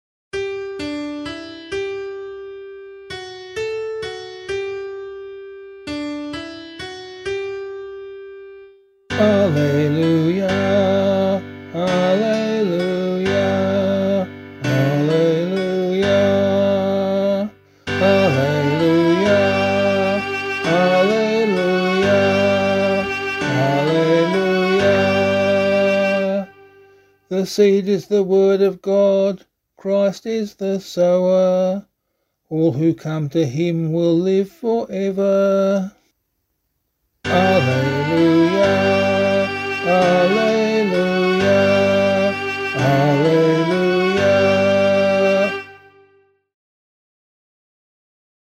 Gospel Acclamation for Australian Catholic liturgy.
045 Ordinary Time 11 Gospel B [LiturgyShare F - Oz] - vocal.mp3